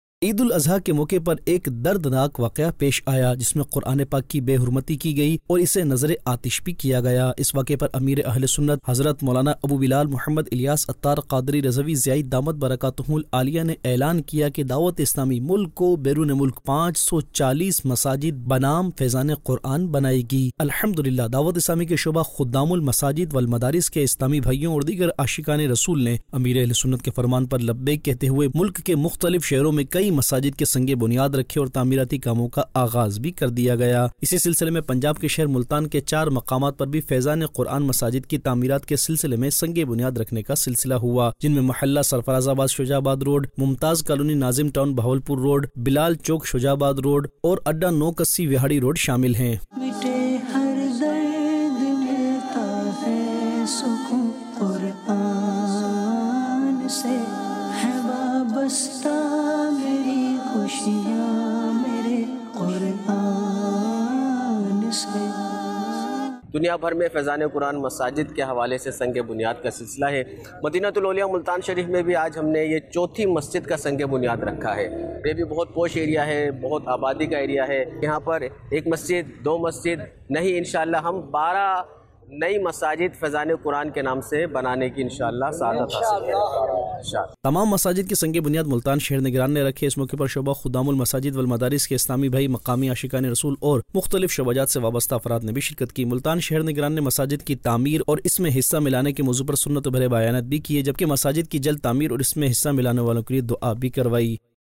News Clips Urdu - 17 August 2023 - Ameer e Ahlesunnat Kay Farman Par 540 Masajid Binaam Faizan Quran Ki Tameerat Jari Aug 24, 2023 MP3 MP4 MP3 Share نیوز کلپس اردو - 17 اگست 2023 - امیر اہلسنت کے فرمان پر 540 مساجد بنام فیضان قرآن کی تعمیرات جاری